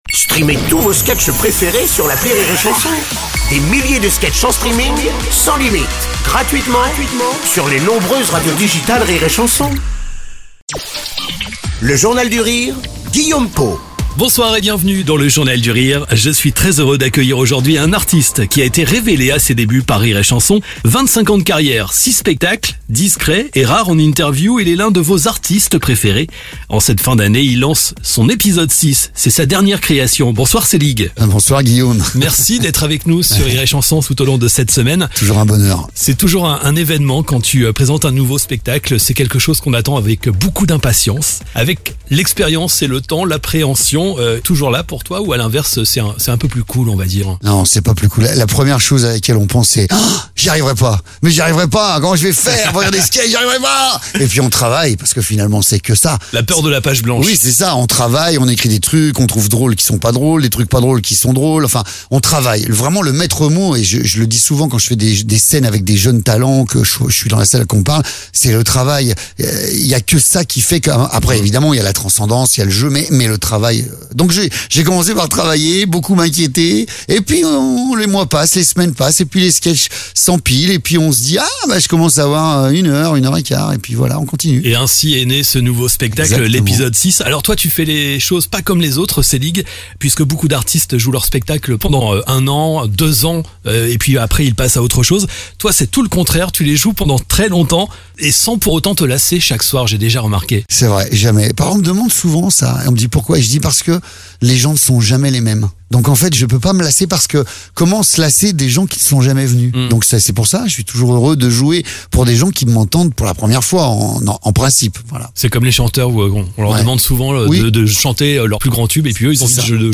Une discussion sans filtre animée par Guillaume Pot.